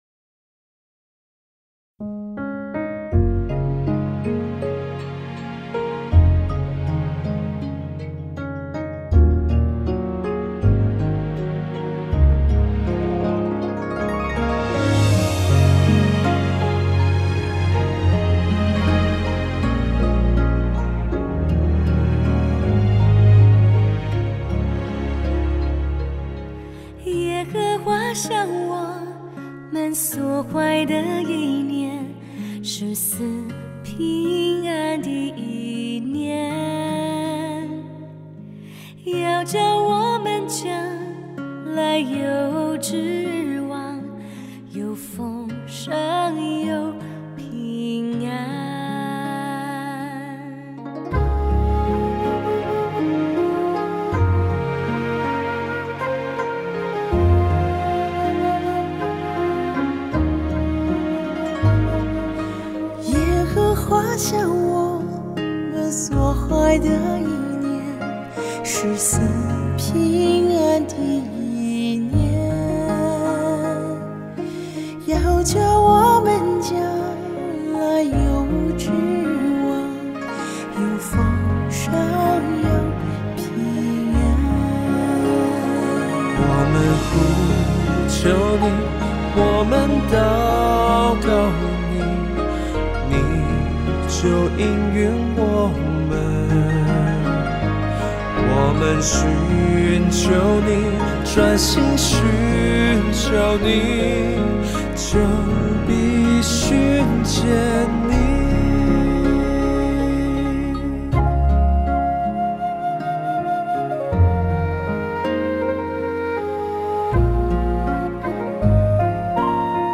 2024-09-22 敬拜诗歌 | 预告